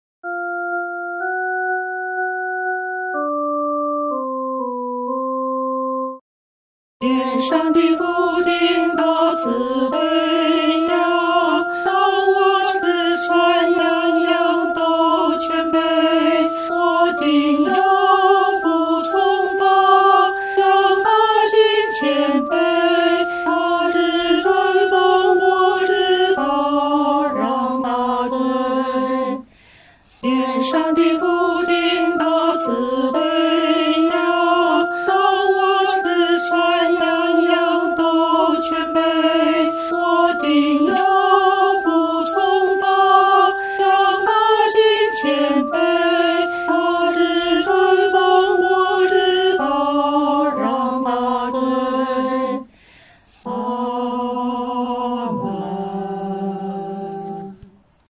女低
本首圣诗由网上圣诗班 (南京）录制